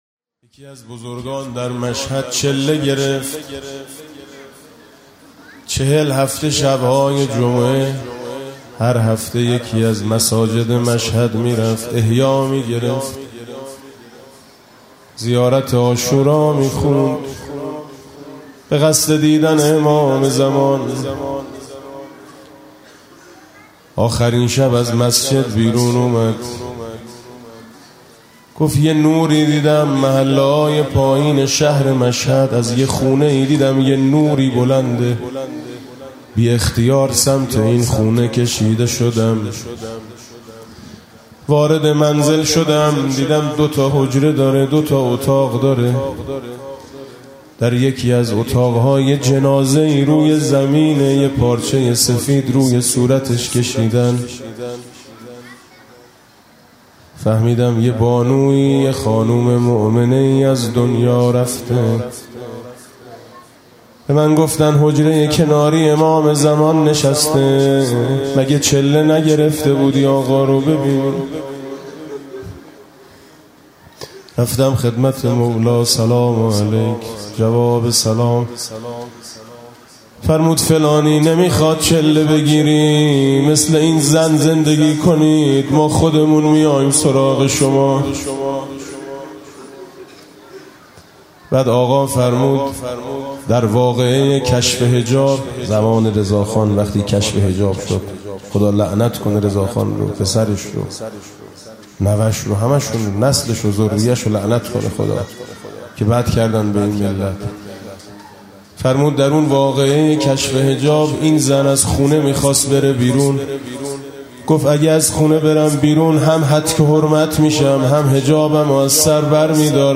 روایت